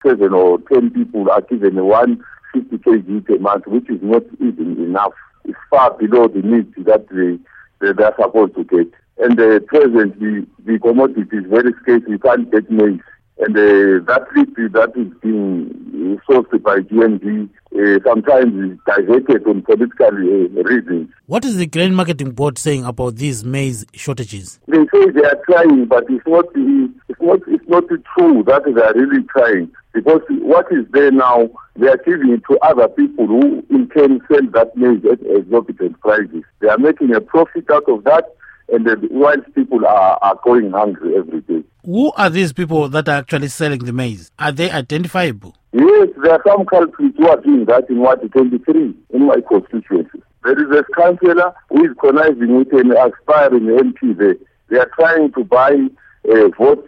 Interview With Patrick Dube